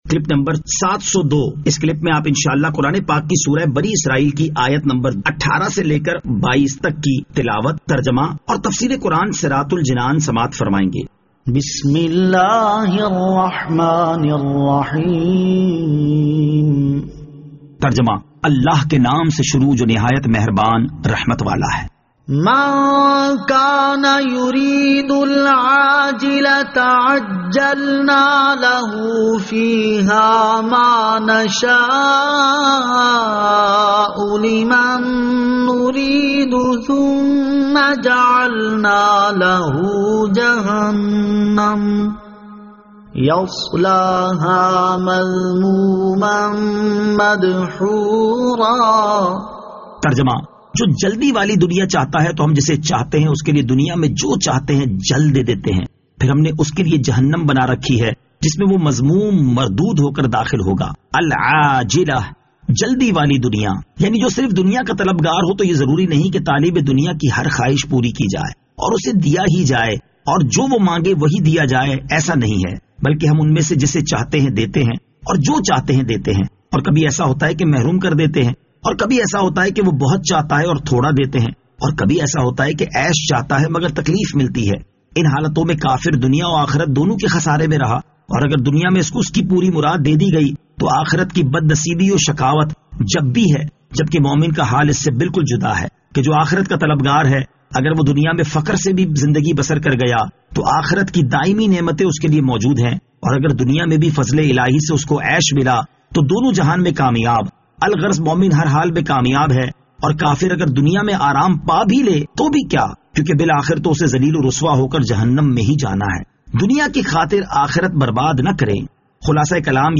Surah Al-Isra Ayat 18 To 22 Tilawat , Tarjama , Tafseer